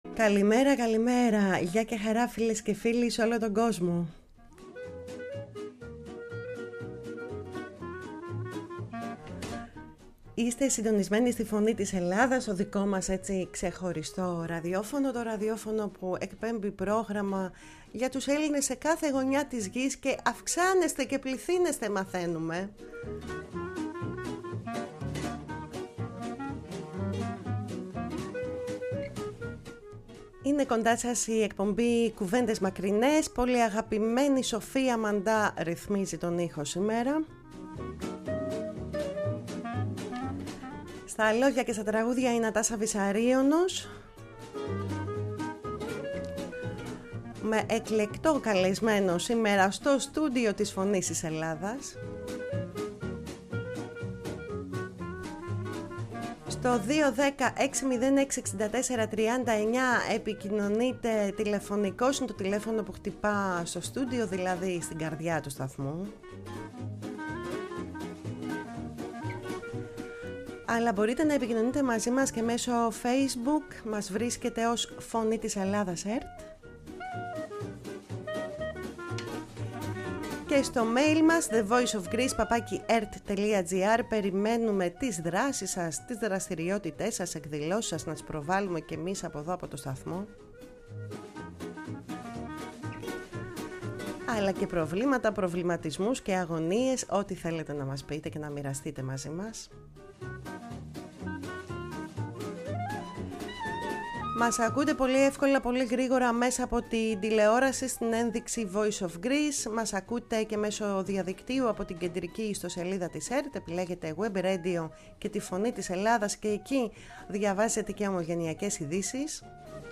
Στο studio της “Φωνής της Ελλάδας”